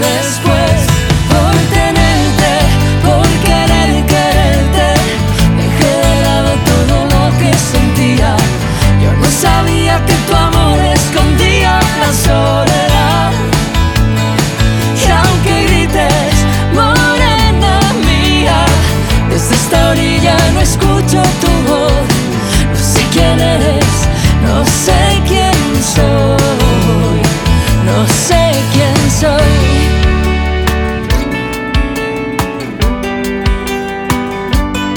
Pop
Жанр: Поп музыка